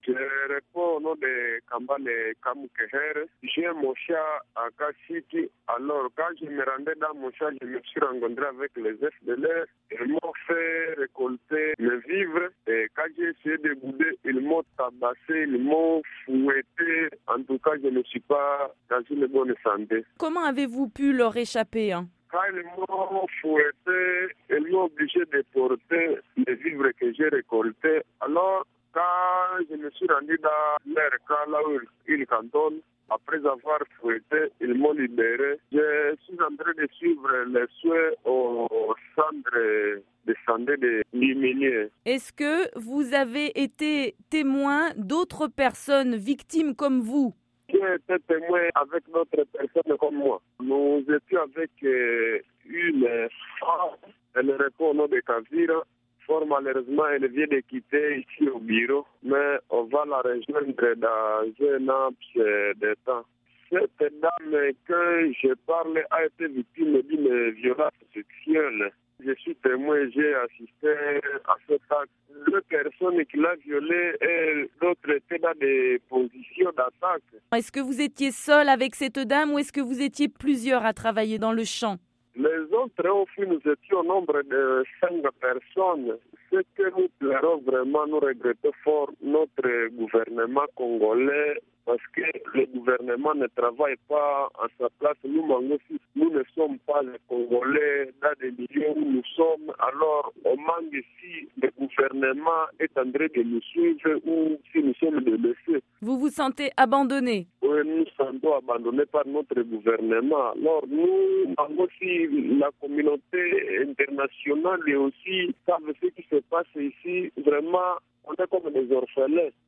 Une victime des FDLR en Territoire de Lubero